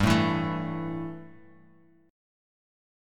A5/G chord
A-5th-G-3,0,2,2,x,x.m4a